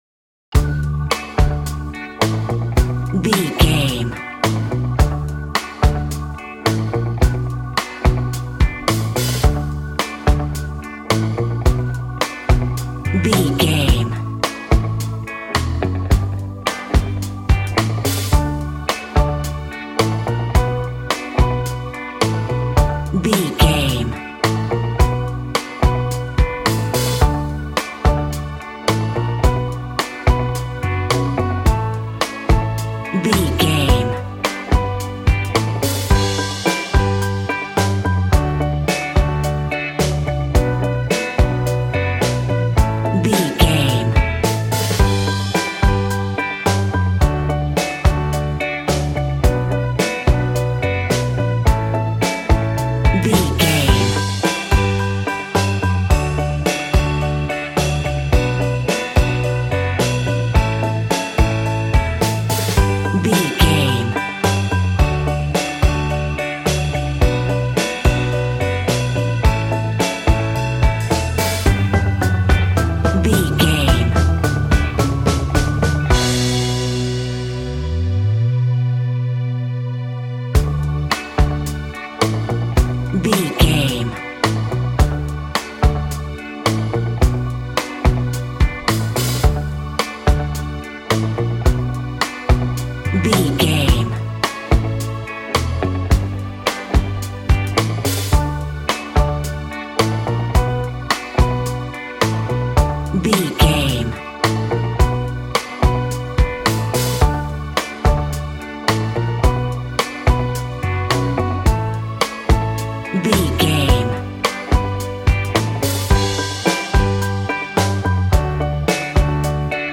Aeolian/Minor
cheerful/happy
double bass
drums
piano